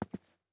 ES_Footsteps Concrete 3.wav